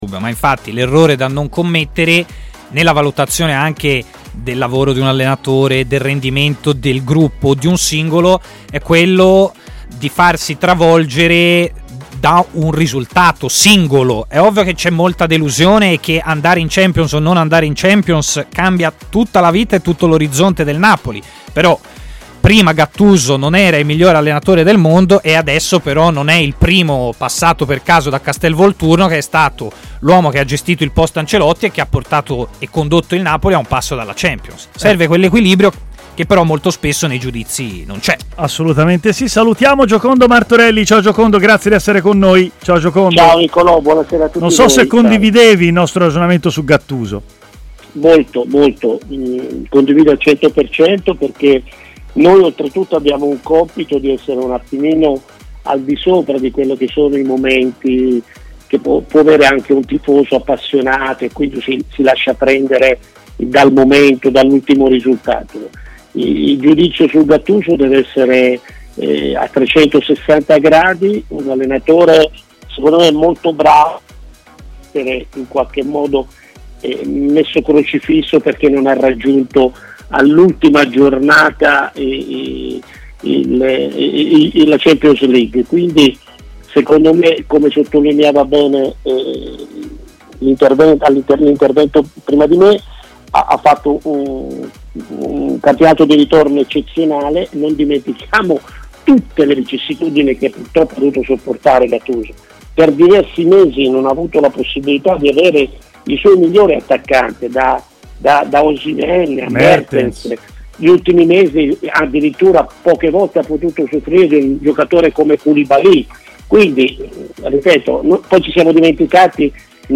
ha parlato in diretta su TMW Radio, durante la trasmissione Stadio Aperto